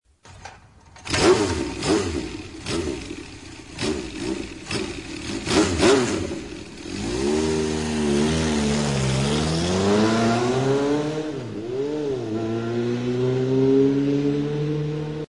جلوه های صوتی
دانلود آهنگ افکت سه بعدی روشن و دور شدن موتورسیکلت از افکت صوتی حمل و نقل